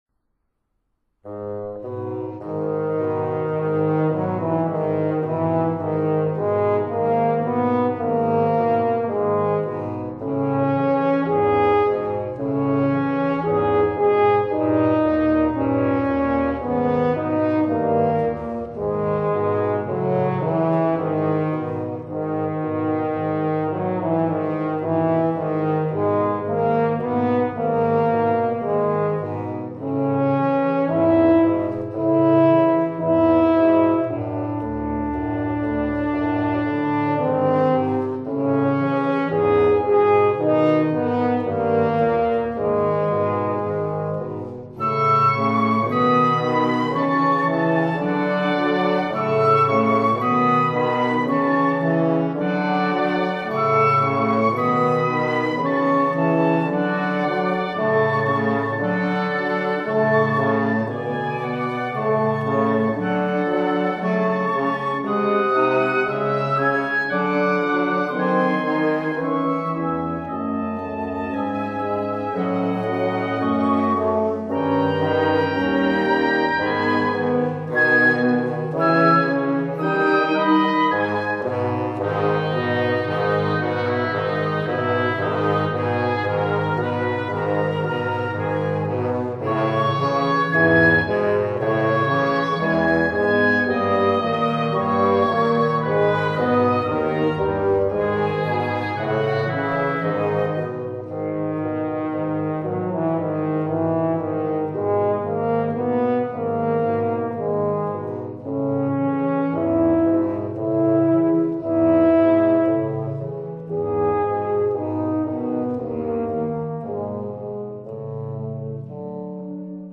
(transcr.wind quintet)